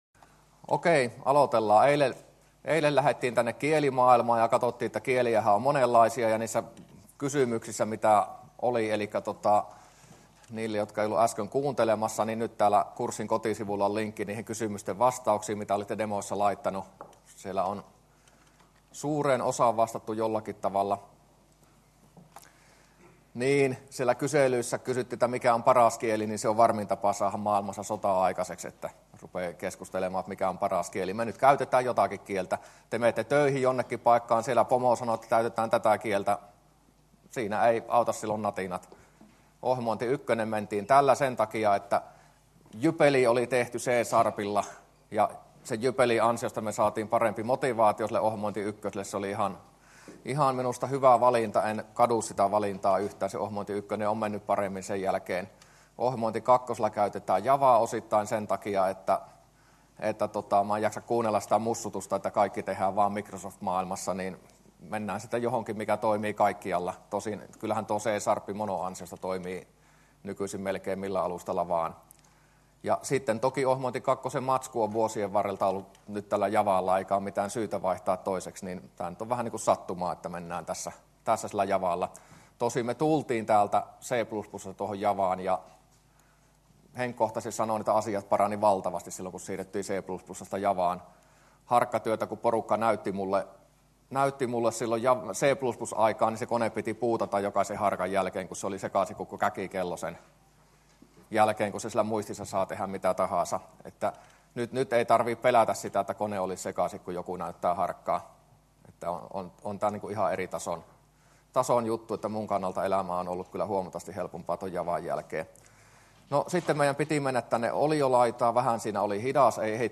luento06a